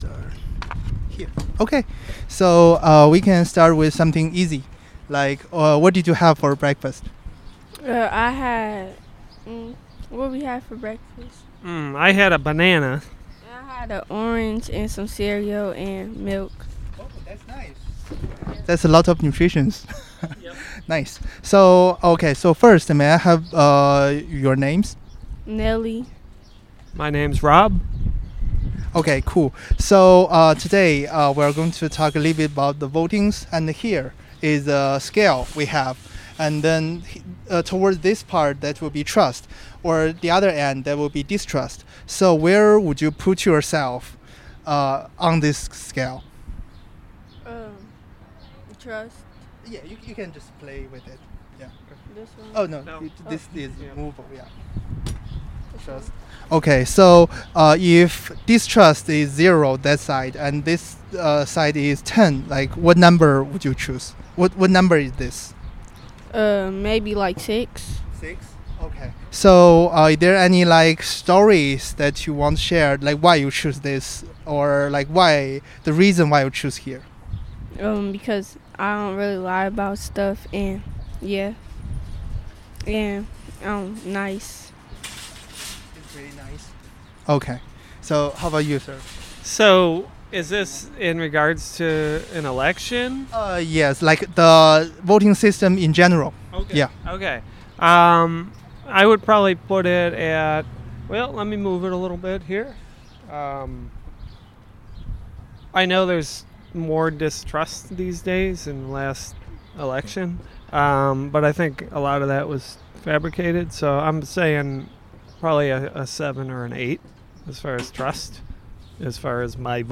Interview
Location Outpost Natural Foods